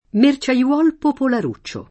merciaiolo
merciaiolo [ mer © a L0 lo ]